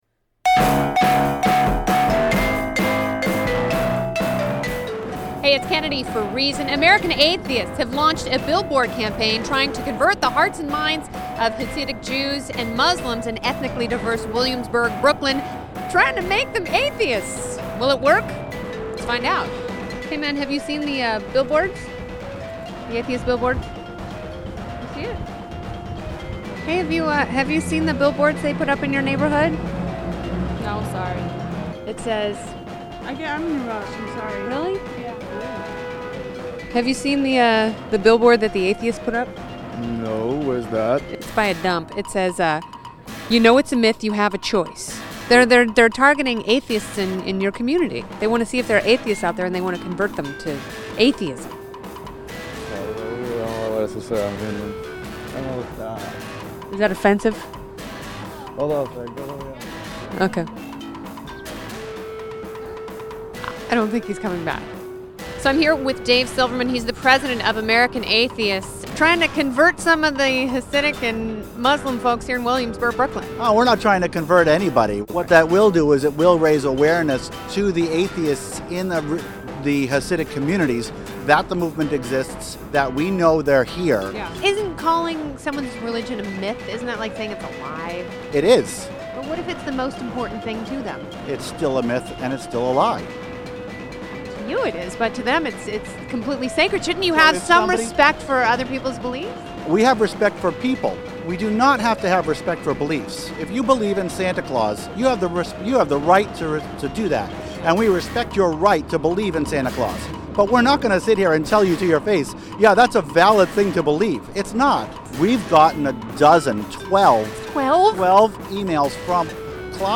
Reason TV correspondent Kennedy braved the 'Burg to ask the locals how they feel about atheists prosthelytizing from signs in the sky.